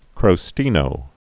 (krō-stēnō)